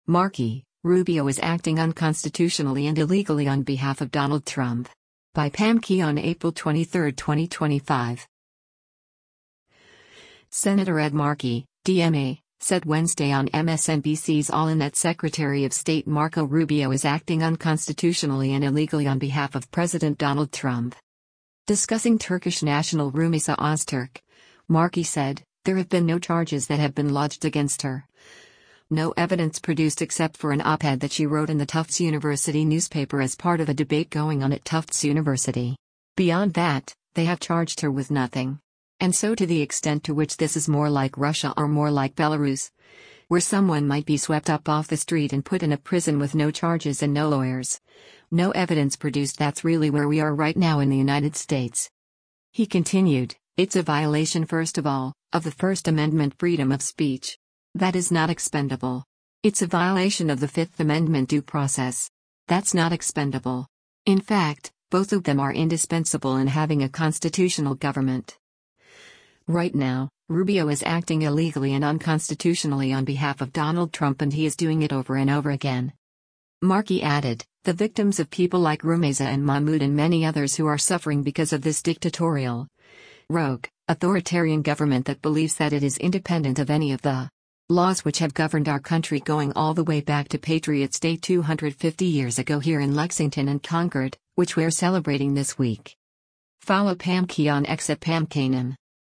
Senator Ed Markey (D-MA) said Wednesday on MSNBC’s “All In” that Secretary of State Marco Rubio is acting “unconstitutionally and illegally” on behalf of President Donald Trump.